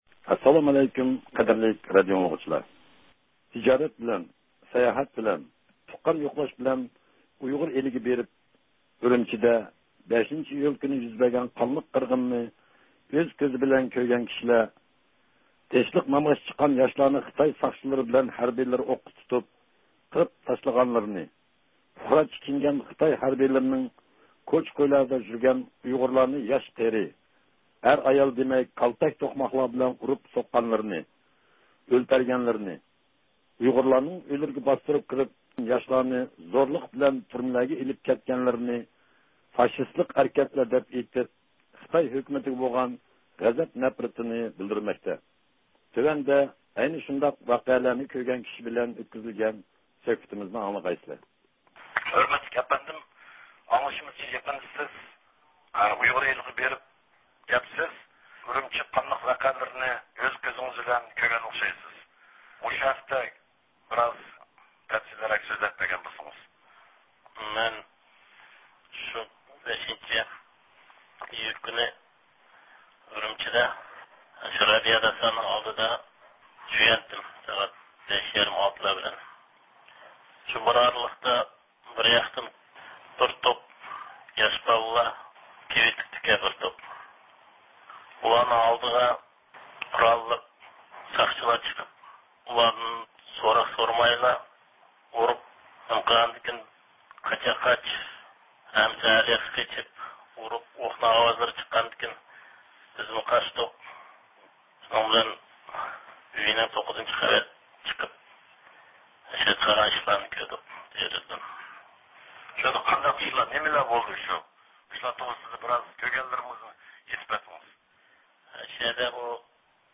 – 5 ئىيۇل ئۈرۈمچى قانلىق ۋەقەسى مەزگىلىدە ئۈرۈمچىدە، دەل رابىيە قادىر سودا سارىيى يېنىدا زىيارەتتە بولۇۋاتقان ۋە قانلىق ۋەقەنى ئۆز كۆزى بىلەن كۆرگەن بىر نەپەر پىشقەدەم ئۇيغۇر زىيالىيسى